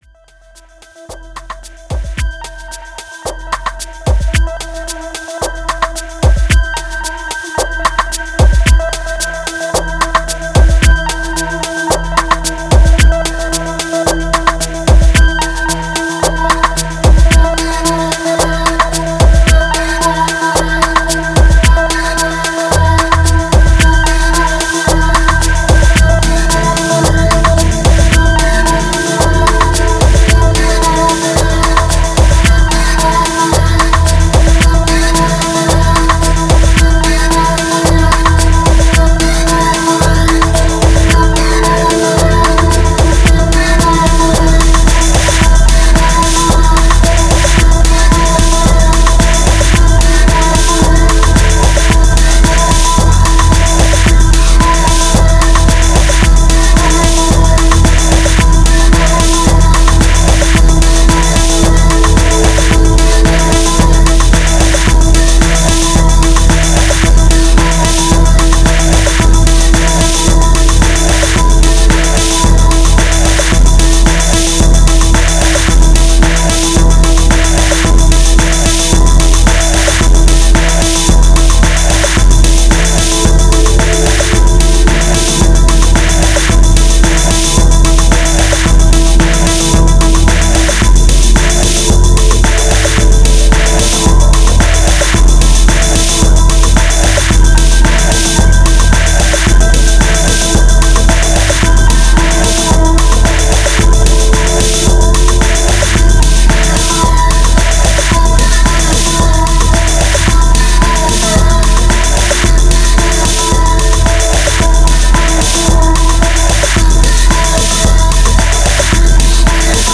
846📈 - 92%🤔 - 111BPM🔊 - 2025-08-03📅 - 1324🌟
Dark Disco Sampler Distortion Ladder Moods Monolith Doom